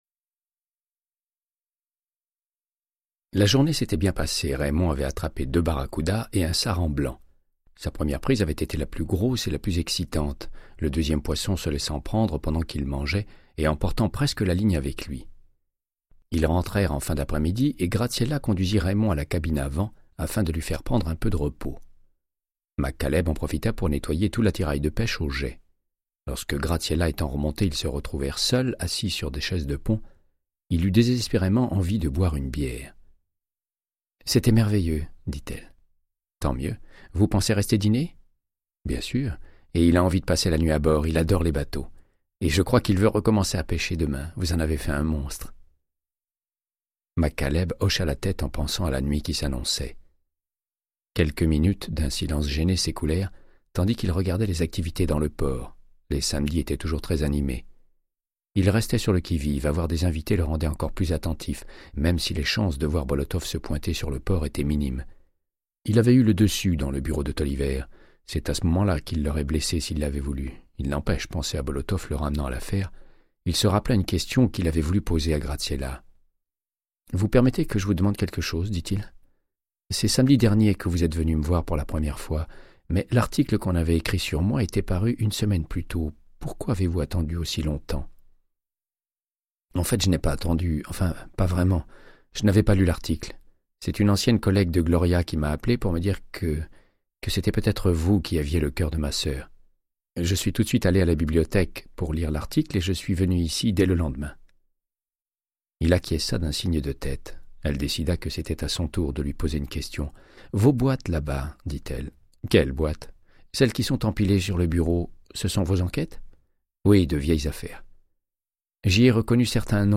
Audiobook = Créance de sang, de Michael Connellly - 89